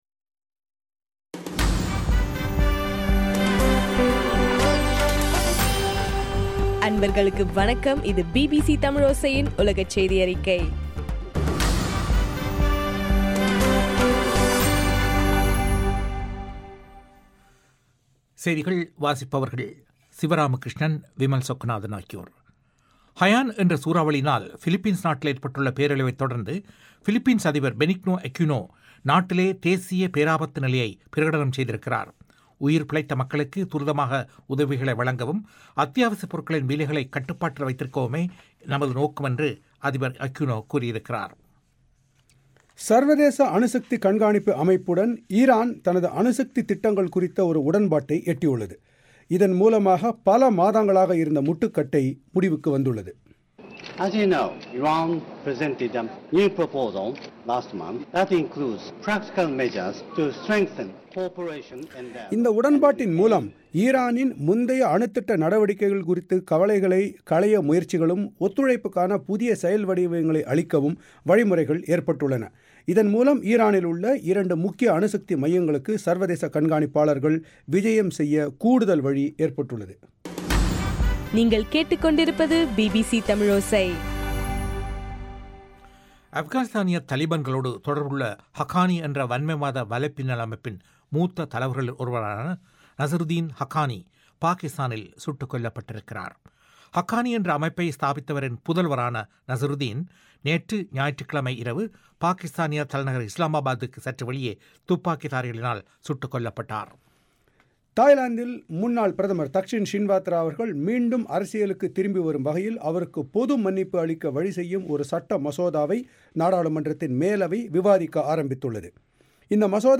இலங்கையின் சக்தி எப்எம் வானொலியில் ஒலிபரப்பான பிபிசி தமிழோசையின் உலகச் செய்தியறிக்கை